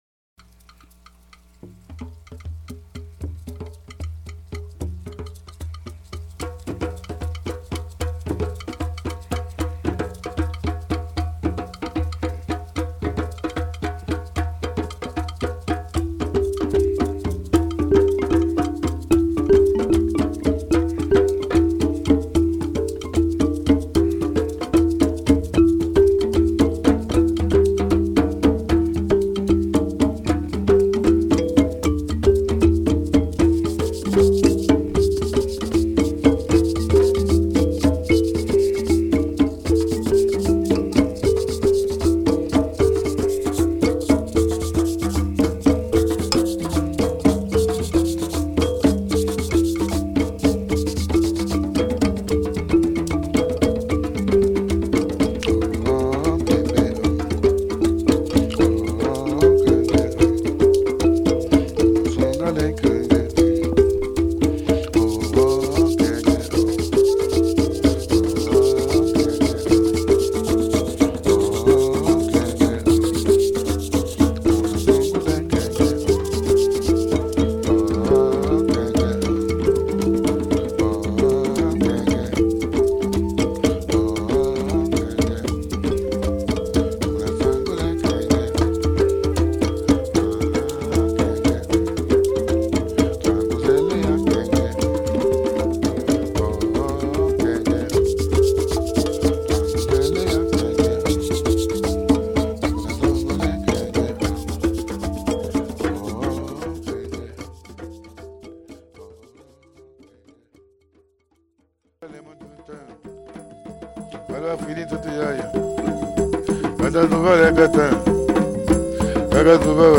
African-inspired